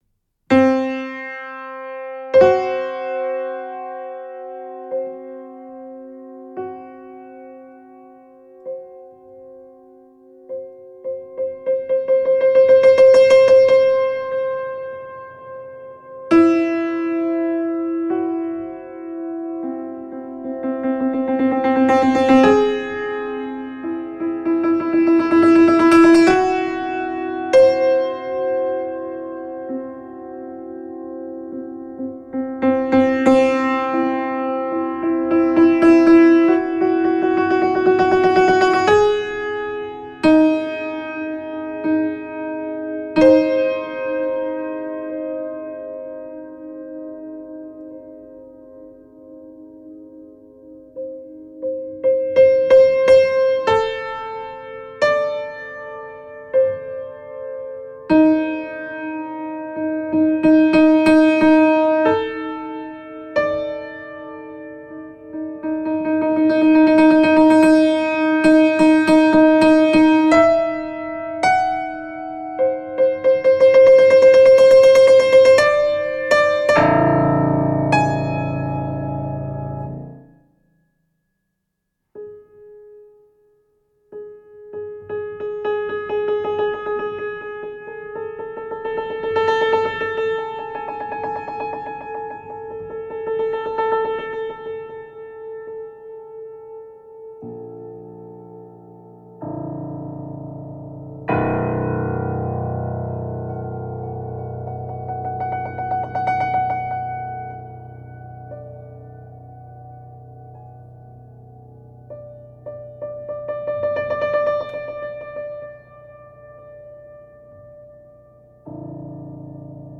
per piano solo